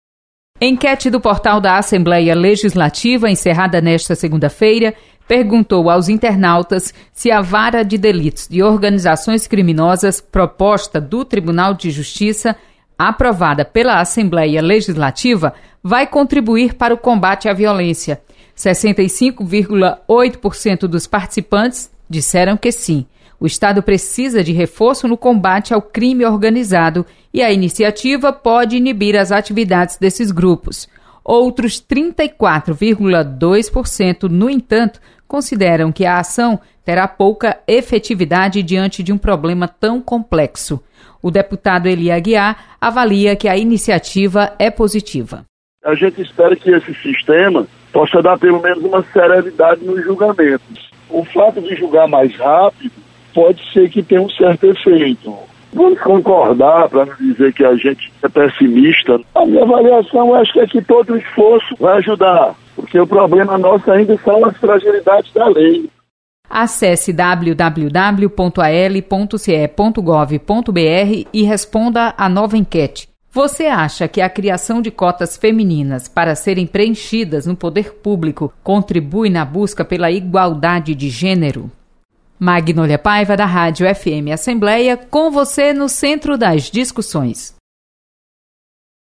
Internautas aprovam criação da vaga de delitos de organizações criminosas. Repórter